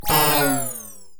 sci-fi_power_down_01.wav